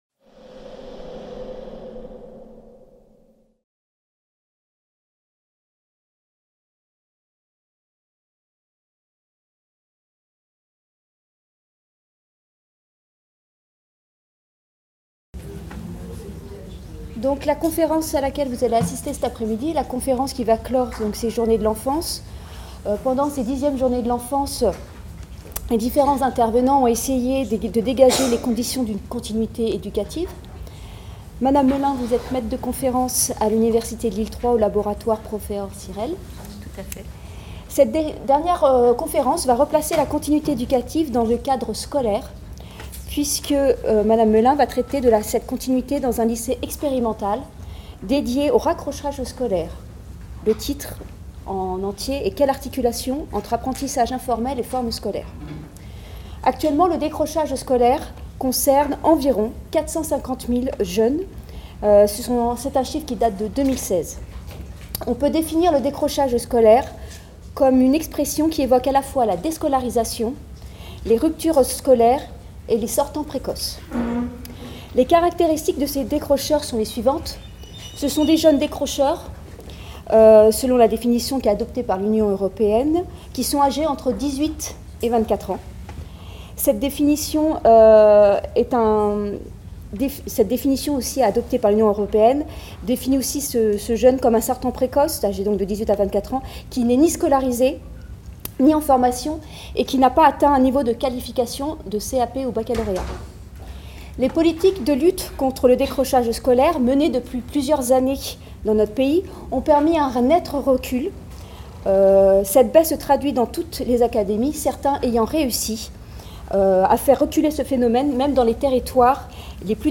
CIRNEF18 | 08 - Conférence 4 : La continuité éducative dans un lycée expérimental dédié au raccrochage scolaire : quelle articulation entre apprentissage informel et forme scolaire ? | Canal U